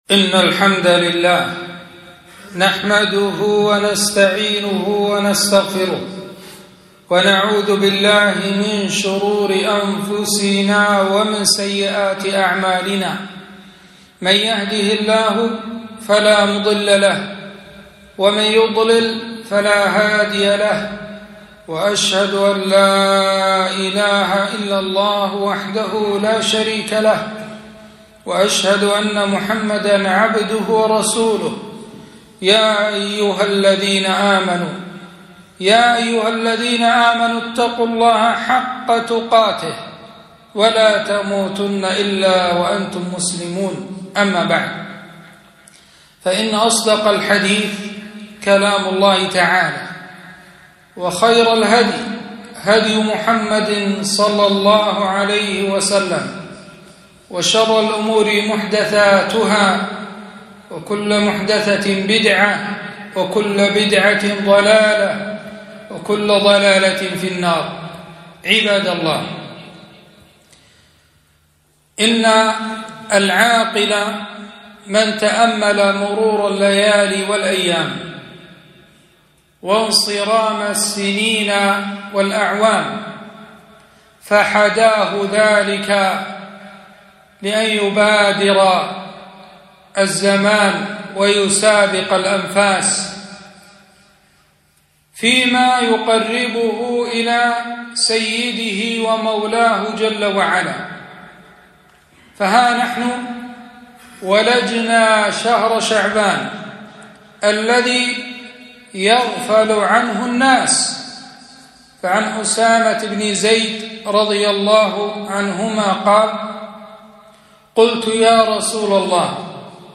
خطبة - شهر شعبان ترفع فيه الأعمال إلى الله